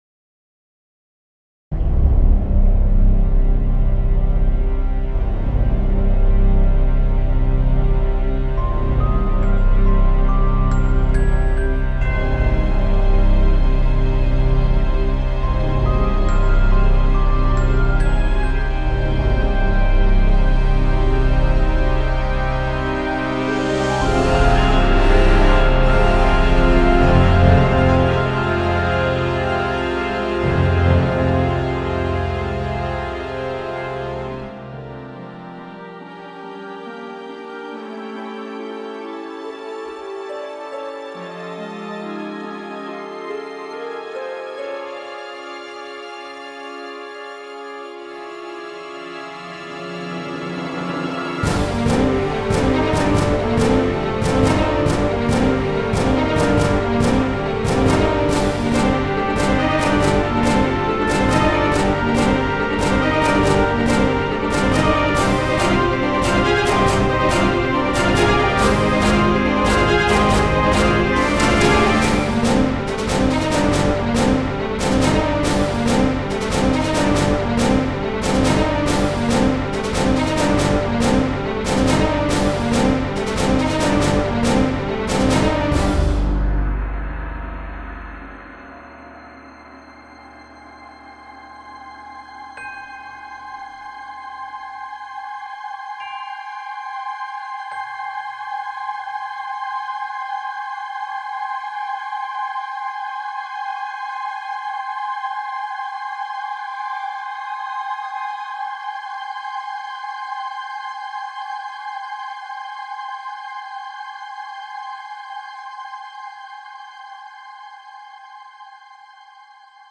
Première partie: on démarre sur des basses profondes, qui soulignent à la fois l'aspect angoissant et l'atmosphère abyssale; cependant le motif au glockenspiel et l'évolution mélodico-harmonique de la compo font rapidement plus vite référence au thriller qu'au genre fantastique-horreur.
Troisième partie: l'idée d'un accord épuré tenu distribué aux cordes + voix est intéressante et fonctionne assez bien ( l'intervention ponctuelle du glockenspiel est plus pertinente que dans le première partie ).
En conclusion, une évolution intéressante, un respect de la charte, mais un manque général de suspense et de présence angoissante...